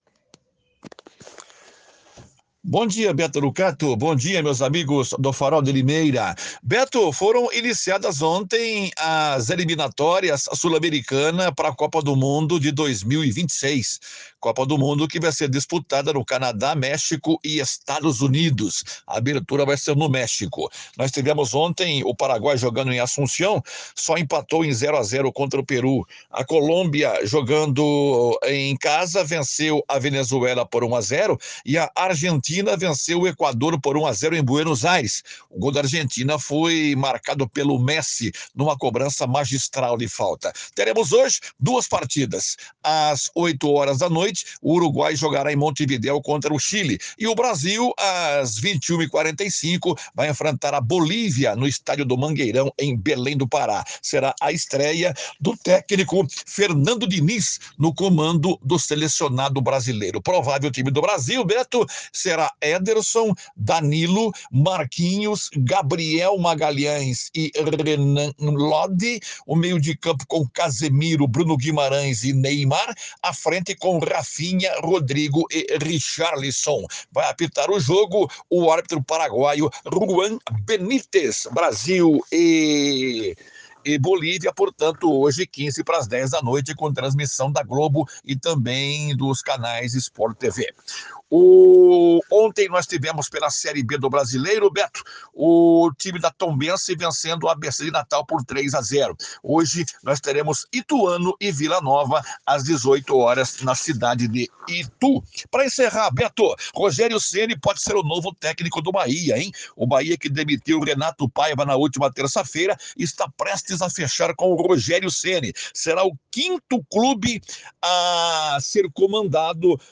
boletim